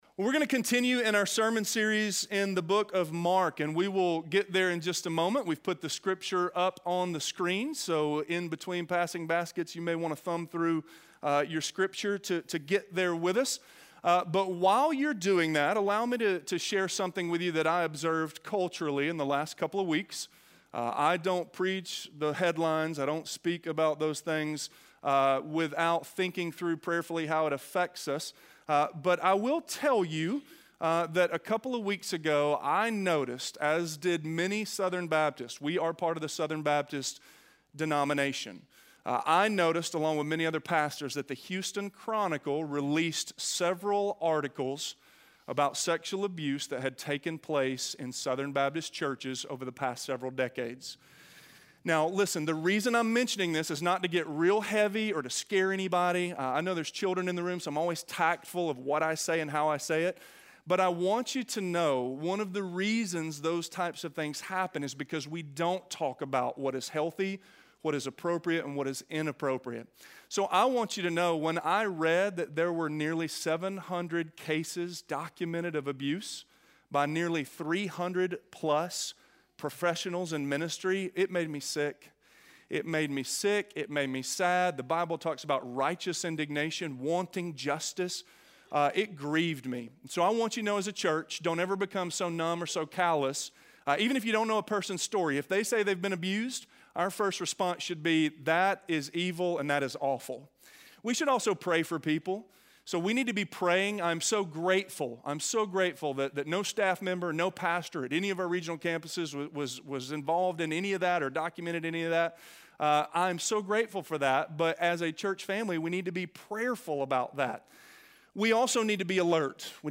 Open Eyes, Open Ears - Sermon - Avenue South